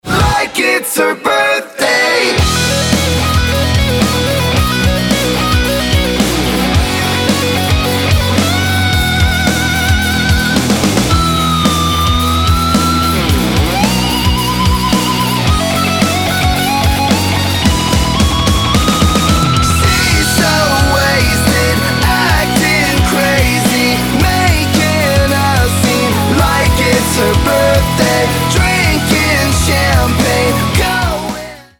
Americká pop-punková skupina